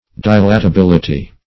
Search Result for " dilatability" : The Collaborative International Dictionary of English v.0.48: Dilatability \Di*la`ta*bil"i*ty\, n. [Cf. F. dilatabilit['e].]